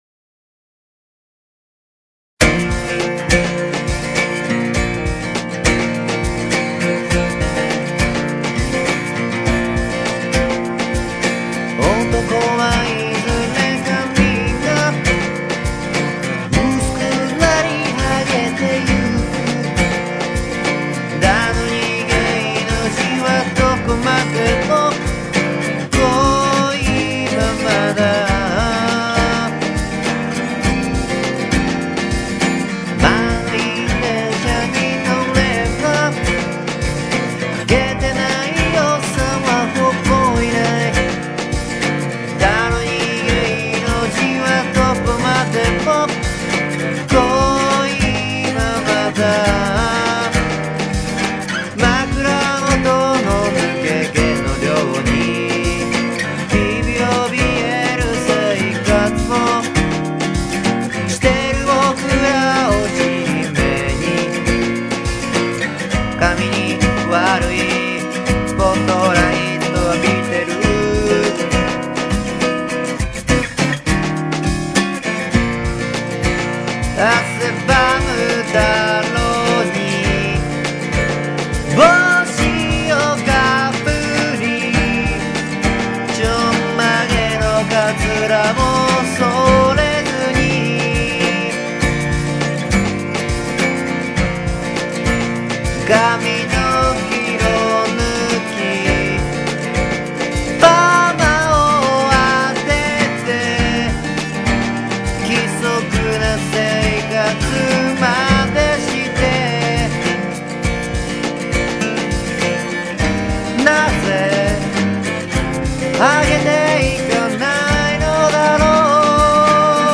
アコギとドラムのみというアンバランスな感じがたまらない！
一番てきとうに作ったのに、なんかメロディがきれいなんだよね。
テンションがおかしいときに作ったから、結構コードが不思議な感じになったね。
CからE♭とかね